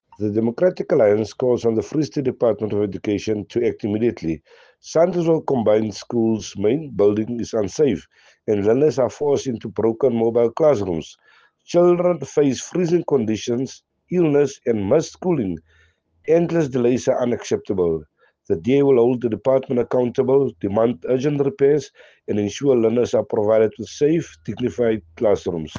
Afrikaans soundbites by Cllr Robert Ferendale and